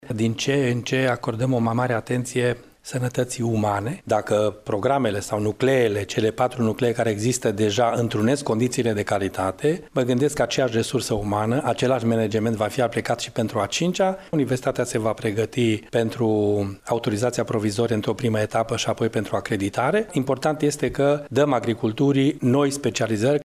Universitatea de Științe Agricole și Medicină Veterinară „Ion Ionescu de la Brad” din Iași a deschis astăzi anul academic prin inaugurarea unui nou corp de clădire.
Secretarul de stat din Ministerul Educaţiei Naţionale, Ştefan Groza a precizat că instituţia academică din Iaşi a depus formele de acreditare pentru cea de-a cincea facultate.